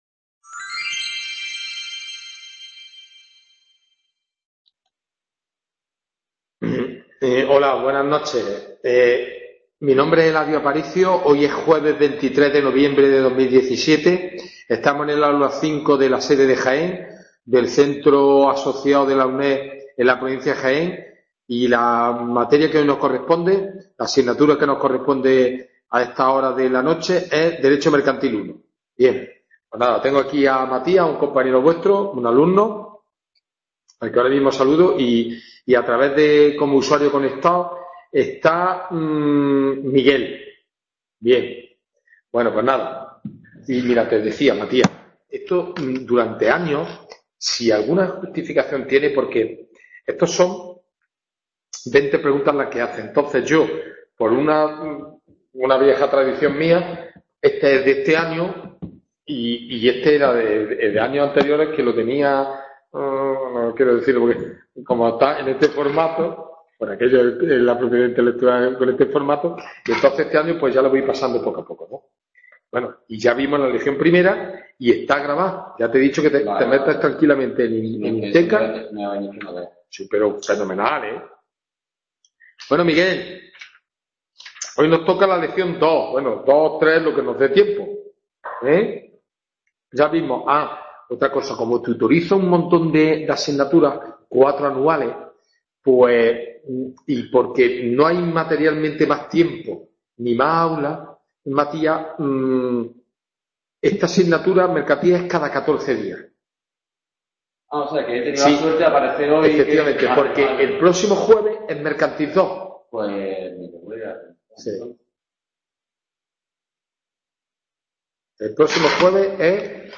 WEB CONFERENCIA